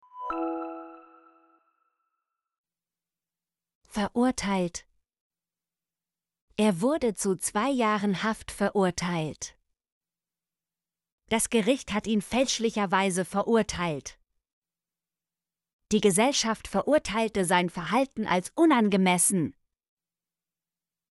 verurteilt - Example Sentences & Pronunciation, German Frequency List